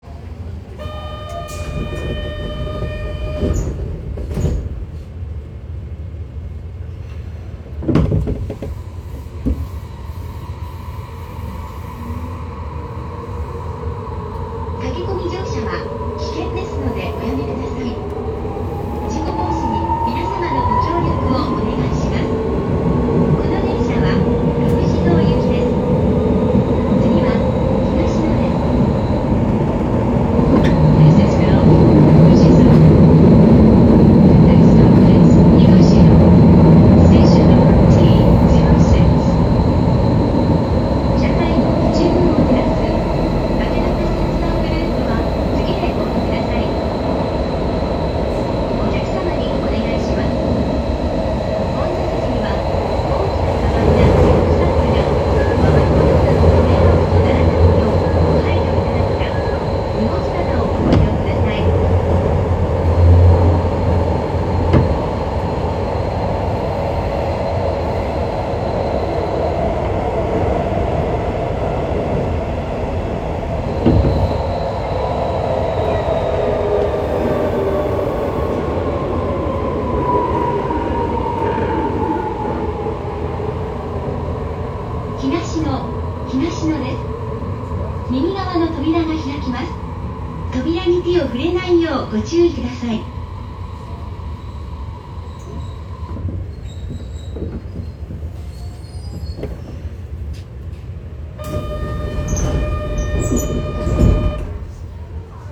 ・50系(更新車)走行音
【東西線】山科→東野（1分45秒：647KB）
上記のGTOは経験故に更新が進んでおり、更新後はあまり特徴のない三菱IGBTとなっています。